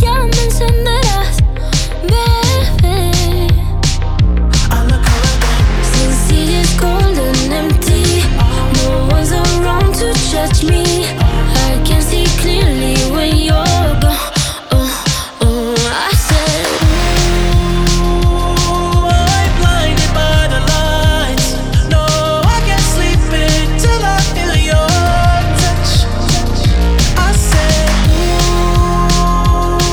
Genre: R&B/Soul